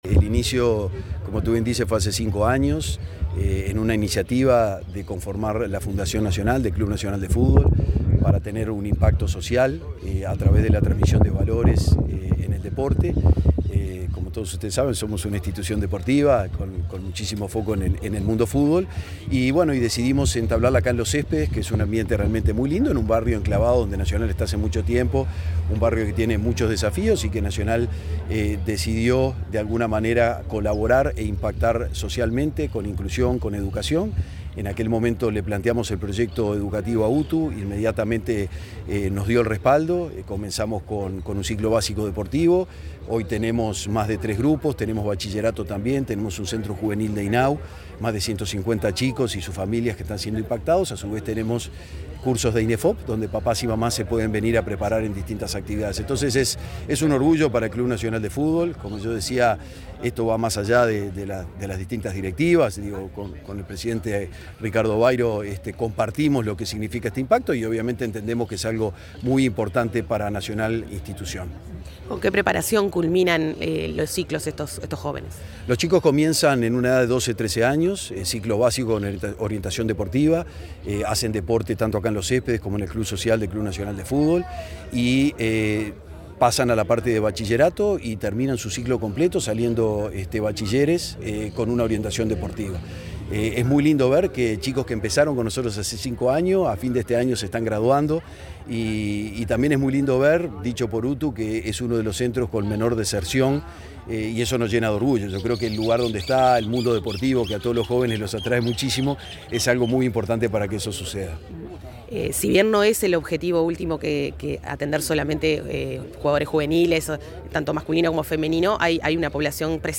En la celebración del 5.° aniversario de la UTU de la Ciudad Deportiva Los Céspedes